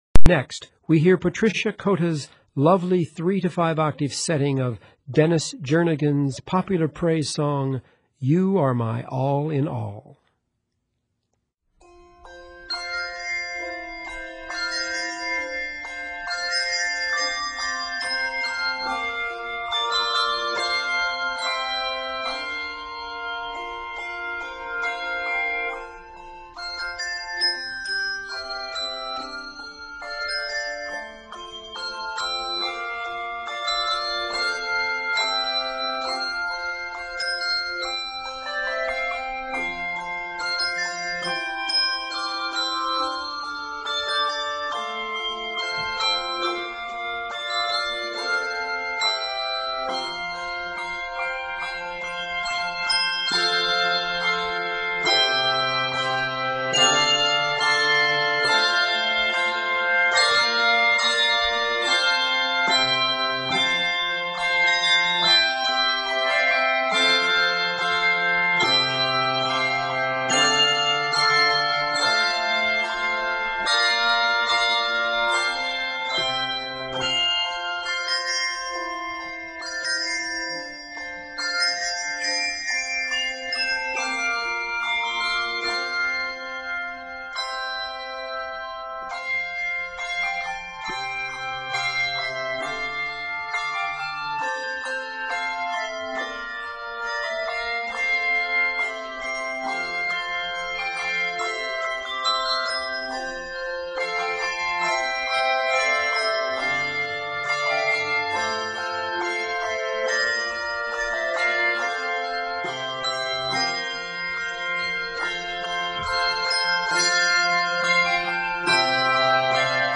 handbells
gently floats above the pulsing broken chords of the bass